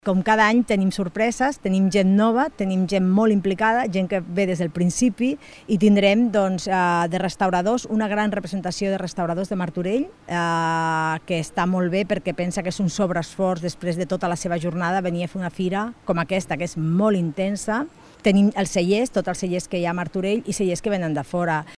Rosa Cadenas, regidora de Promoció Econòmica